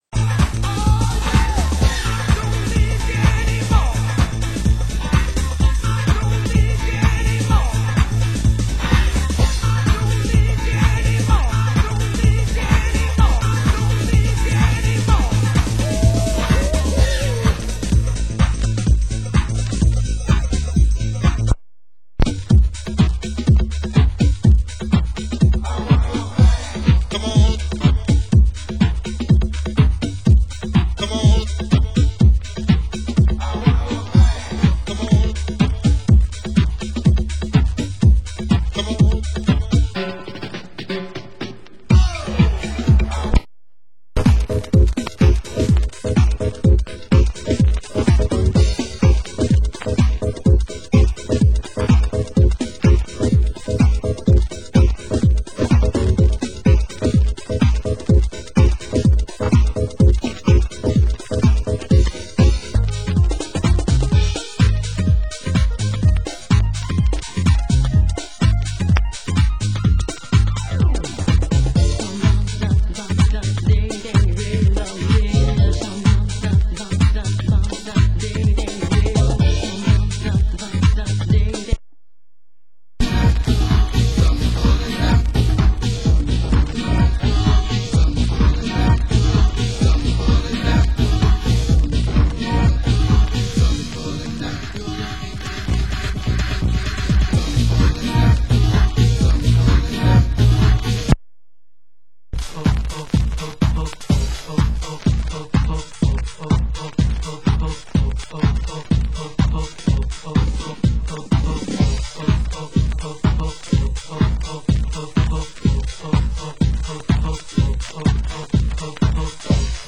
Genre: US House